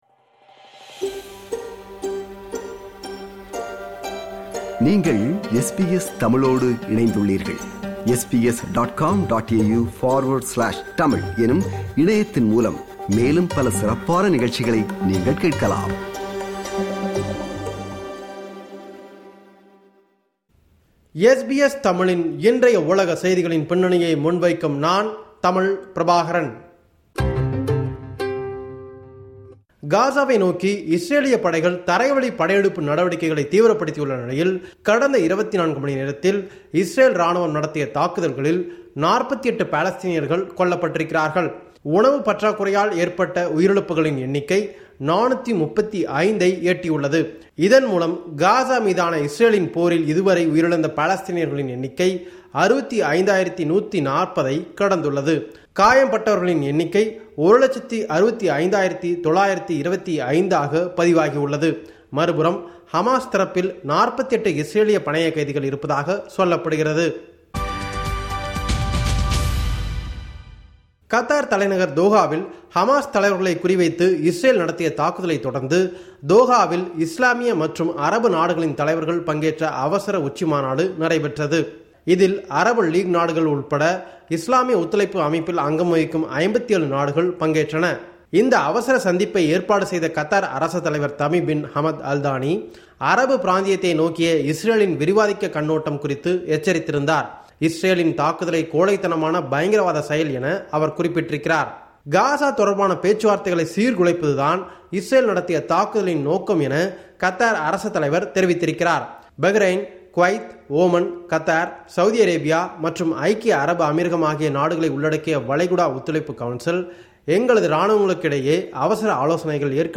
உலகம்: இந்த வார செய்திகளின் தொகுப்பு